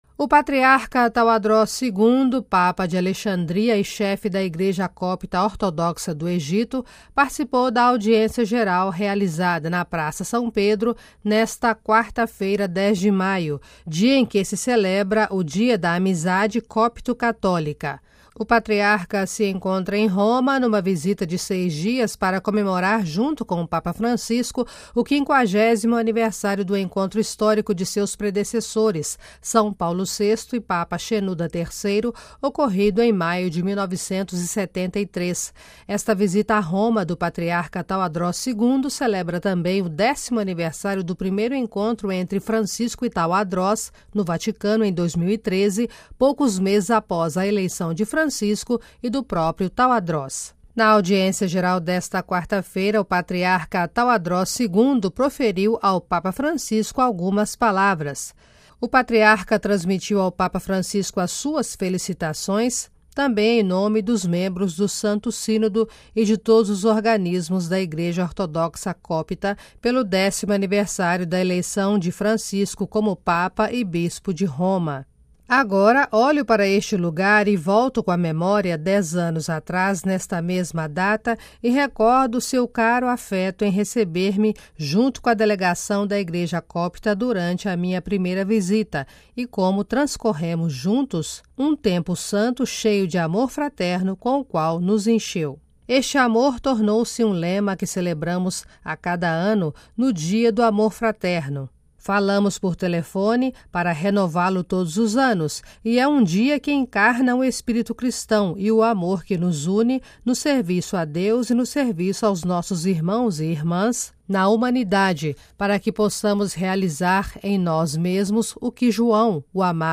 Patriarca Tawadros II na Audiência Geral